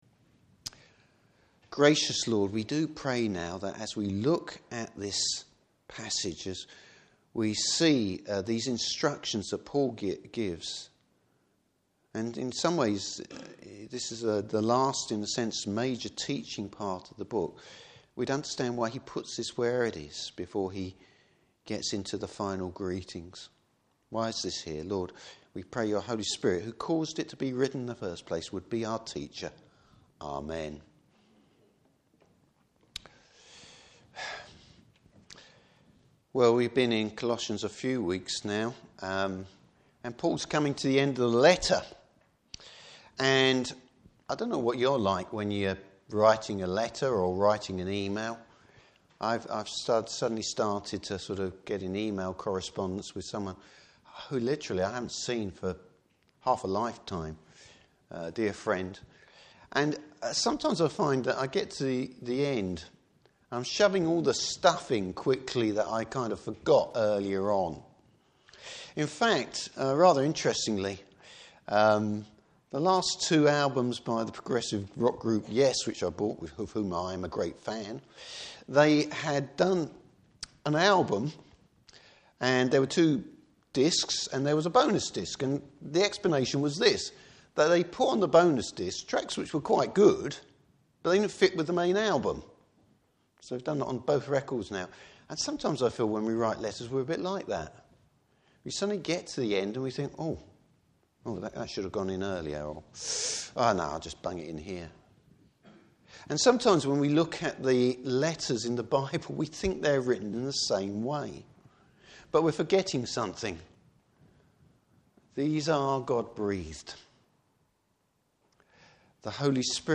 Service Type: Morning Service How we pray and witness and why it matters.